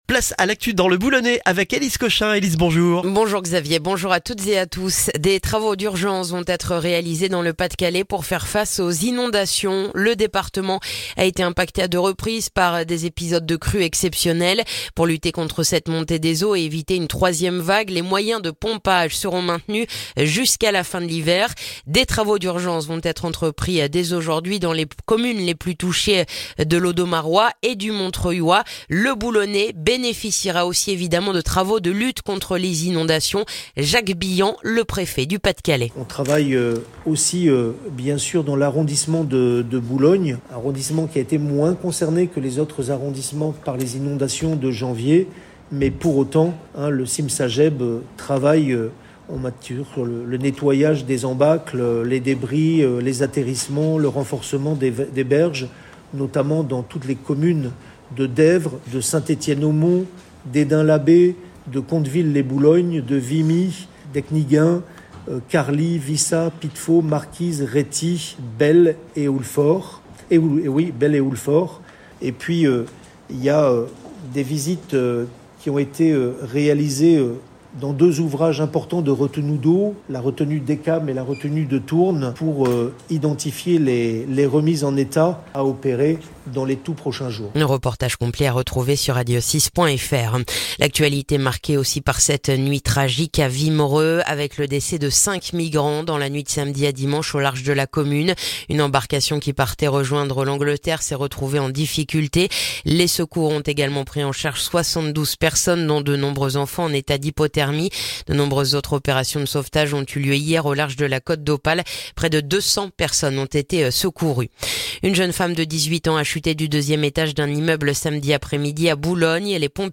Le journal du lundi 15 janvier dans le boulonnais